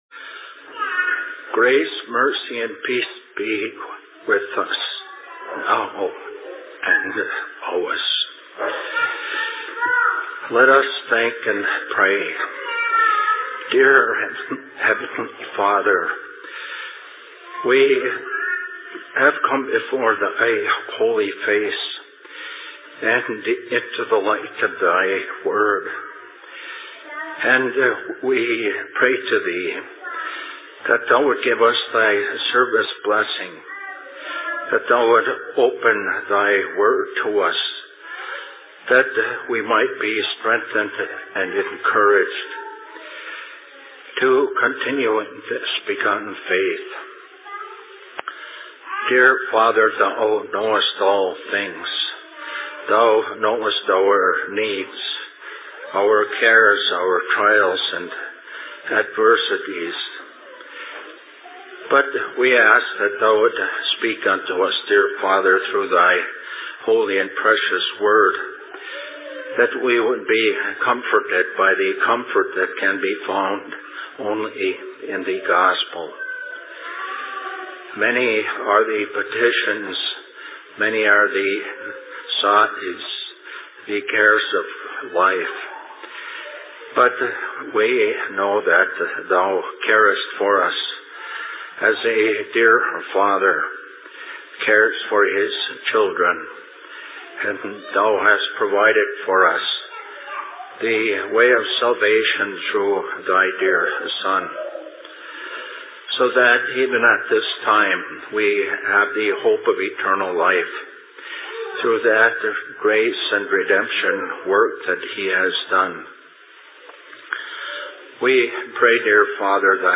Sermon in Ishpeming 20.06.2010
Location: LLC Ishpeming